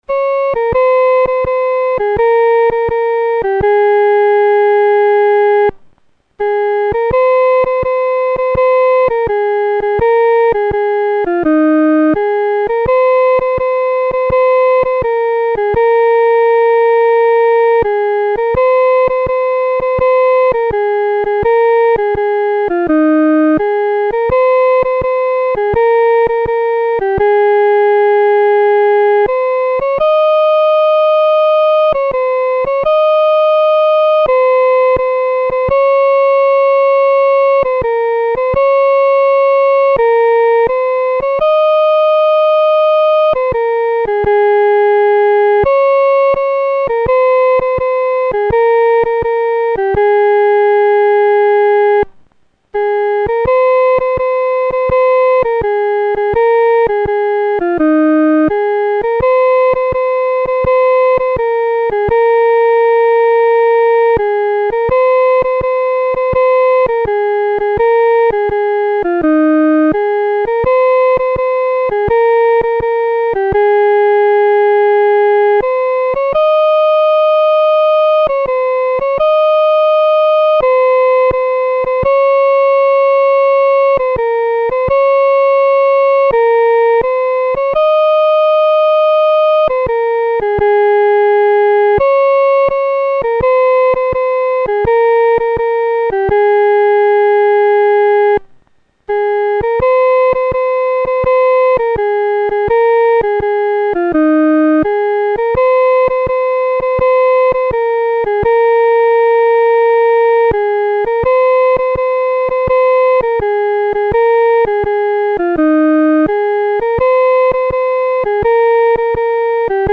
独奏（第一声）